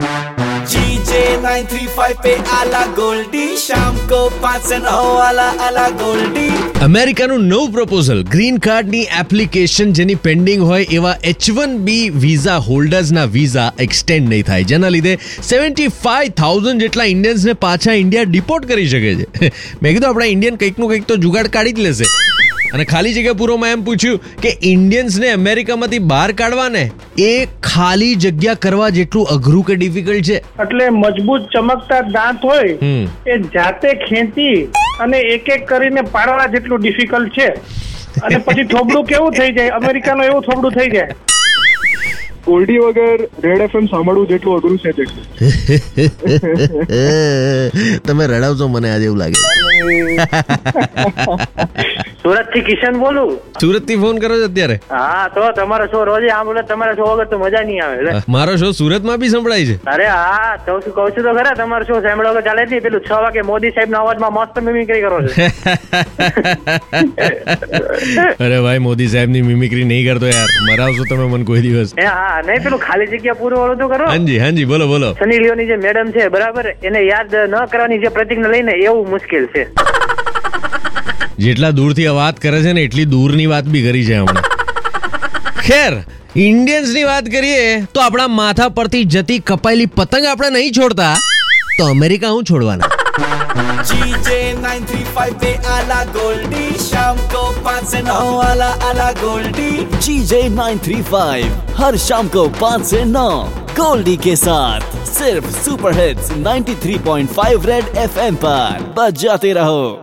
in our daily contest "fill in the blanks" i asked people how much difficult it is to take Indians out or America, what people said was interesting!! listen to know